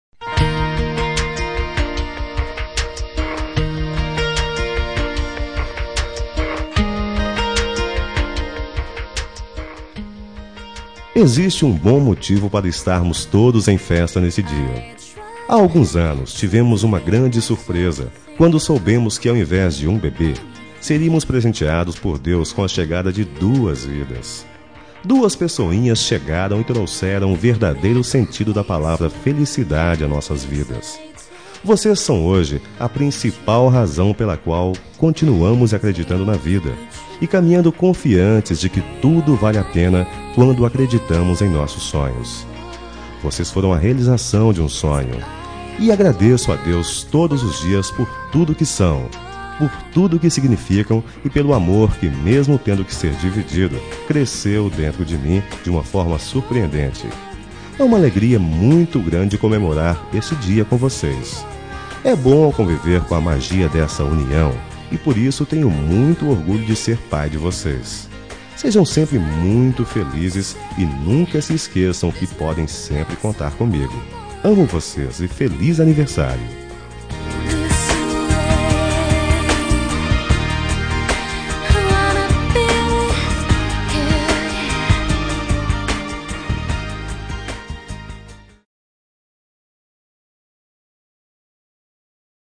Telemensagem de Aniversário de Filha – Voz Masculina – Cód: 1805